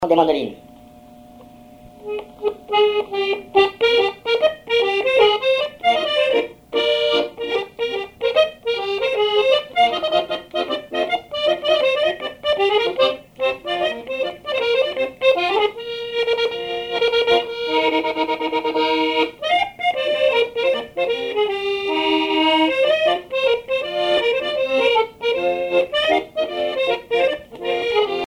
accordéon(s), accordéoniste
Pièce musicale inédite